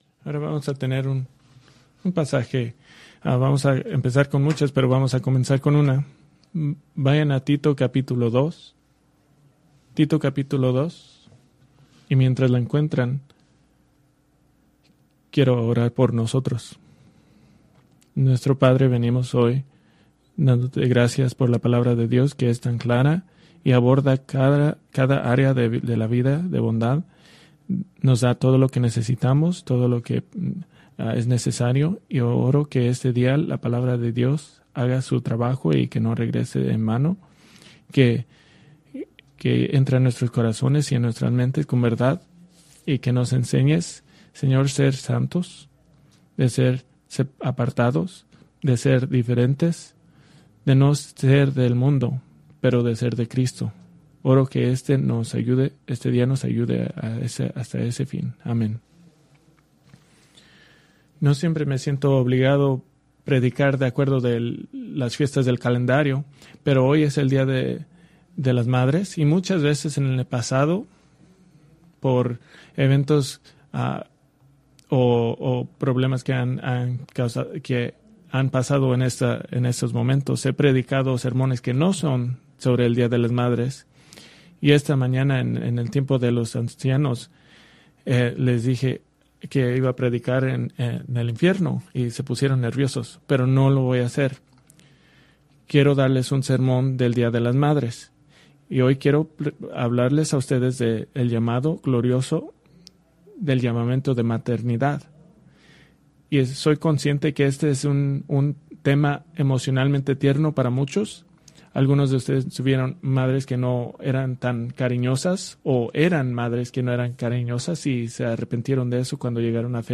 Preached May 11, 2025 from Tito 2:3-4; 1 Timoteo 5:14